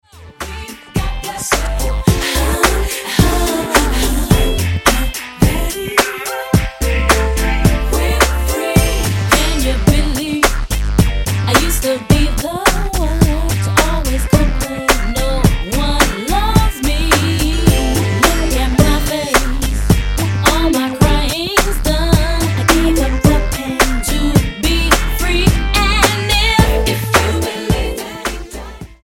STYLE: R&B
contemporary R&B/urban gospel sound
upbeat, funky sound